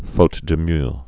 (fōt də myœ)